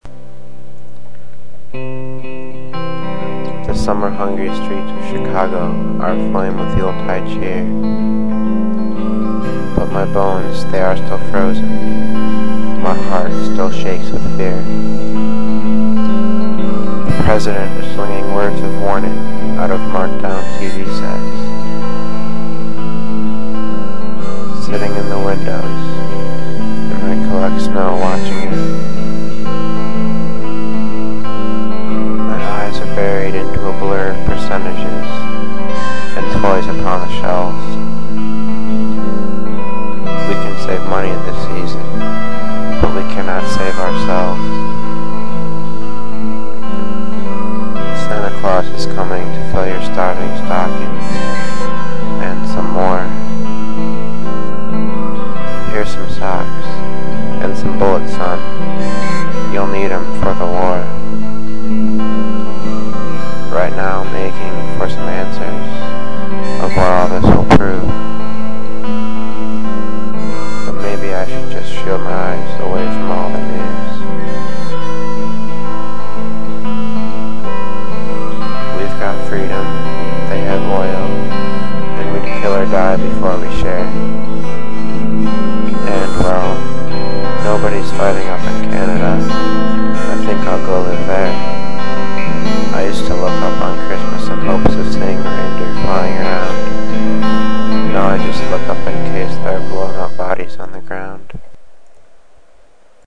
spoken word behind music